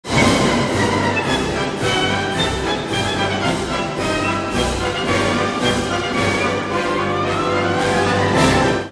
To test the concept, I'm trying a couple of different formats - electronic recordings of music, snippets of sheet music of well-know compositions, and, of course, pictures of cars.